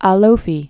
(ä-lōfē)